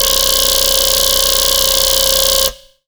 SGLBASS  2-L.wav